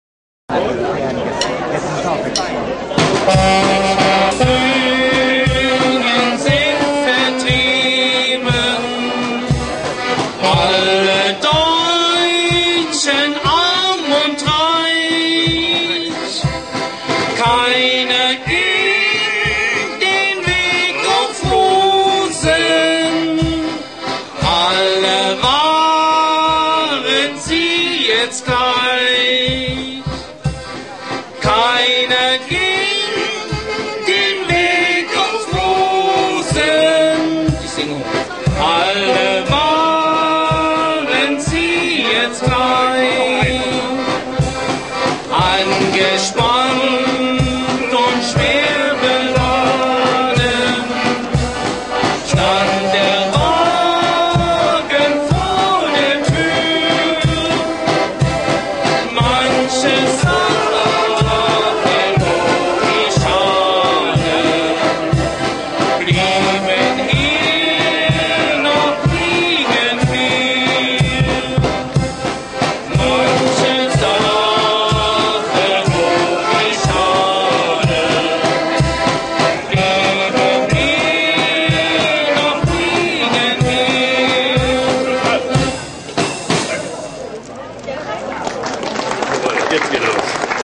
gesungen auf dem Museumsfest 2005 in Linstow